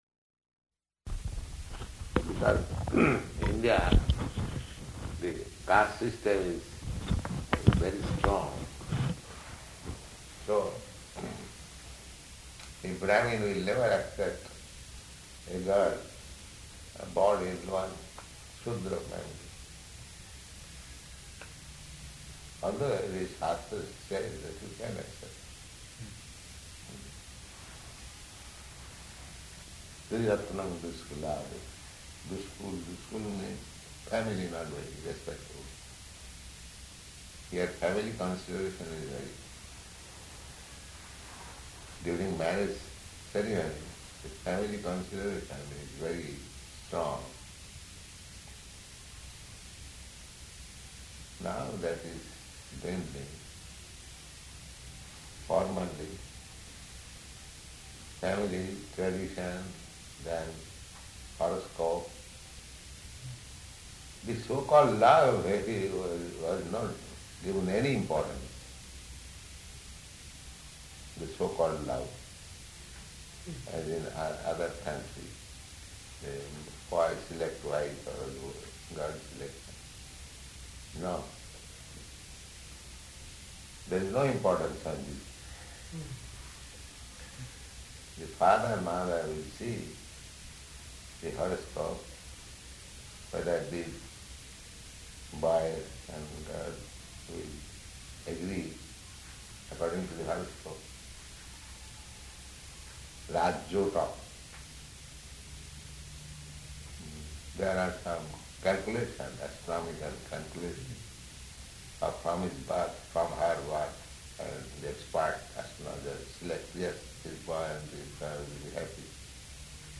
Type: Conversation